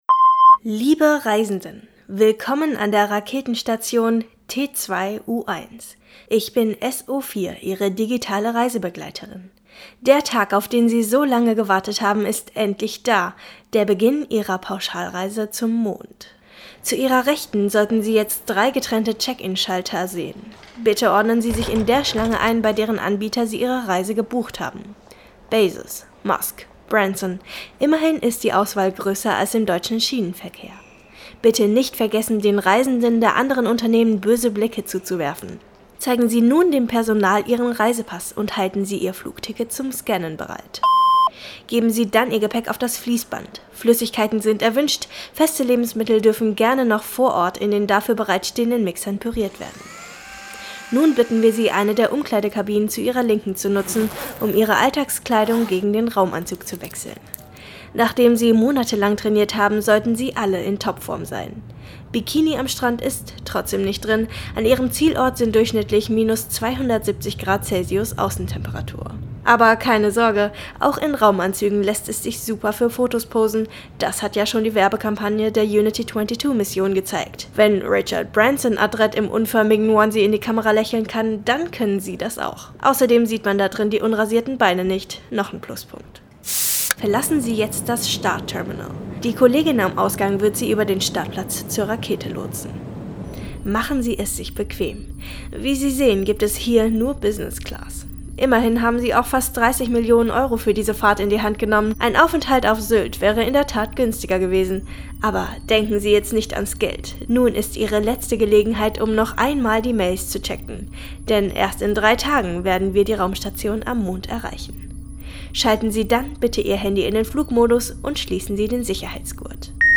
Glosse  Sendung